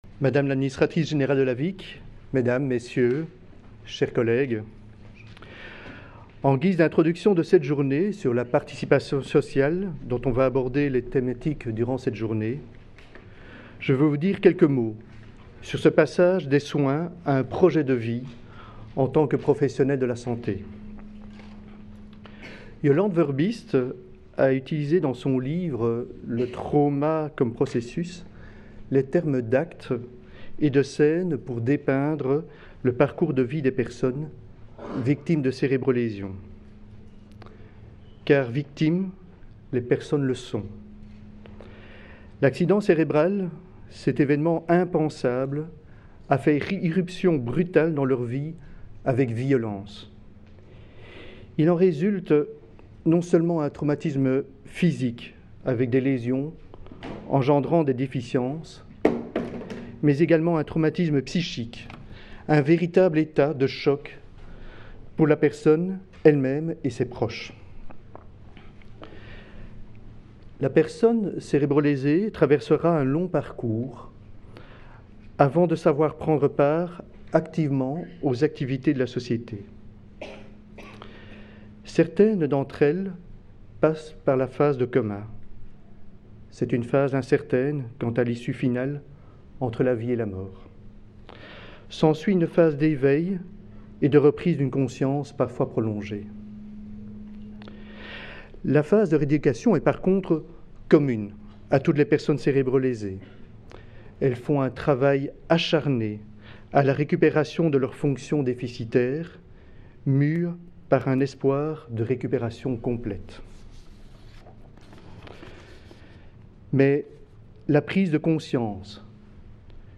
Le 16 mars 2018, Le Centre Ressources Lésion Cérébrale organisait sa première journée d’étude. Elle avait pour titre « Cérébrolésion et participation sociale: passer d’un projet de soins à un projet de vie ».